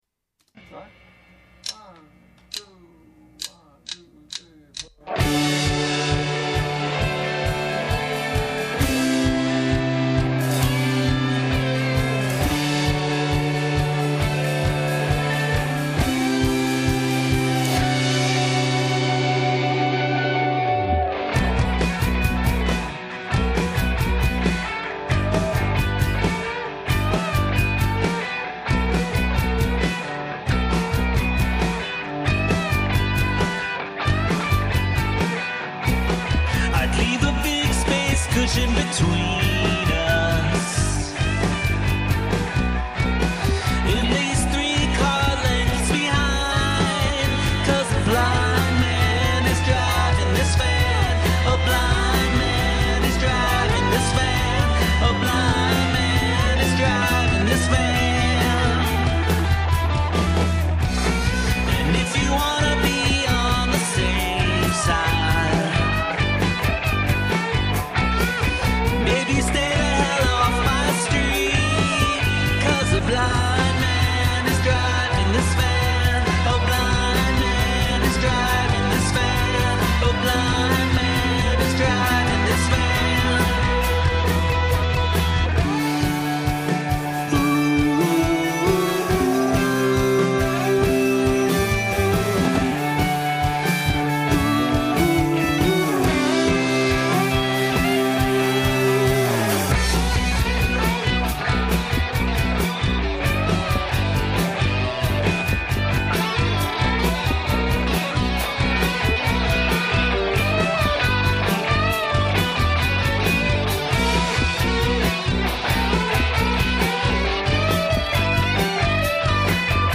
I don’t usually automate a lot with micro level adjustments but on the harsh lead guitar playing the relentless riff I ended up automating the volume levels on almost every beat to make it pump more and give it energy, while at the same time tucking the overall level back down some.